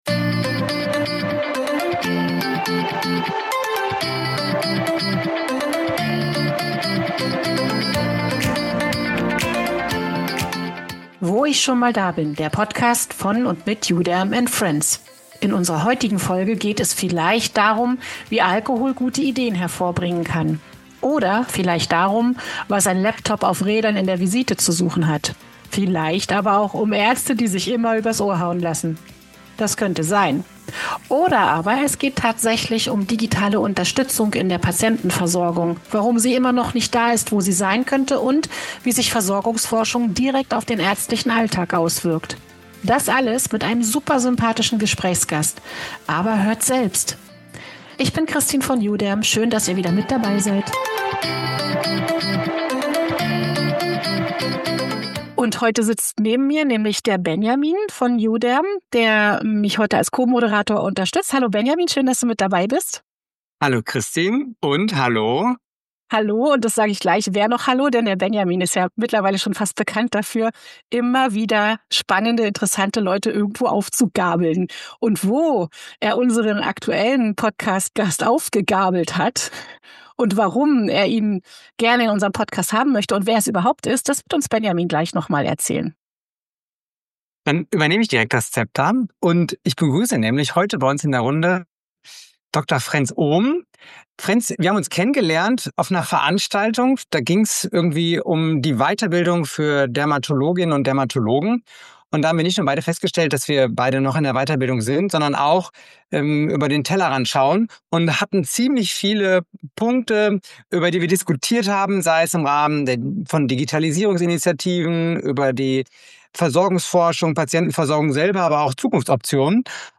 Oder aber es geht tatsächlich um digitale Unterstützung in der Patientenversorgung, warum sie immer noch nicht da ist, wo sie sein könnte und wie sich Versorgungsforschung direkt auf den ärztlichen Alltag auswirkt. Das alles mit einem super sympathischen Gesprächsgast - aber - hört selbst!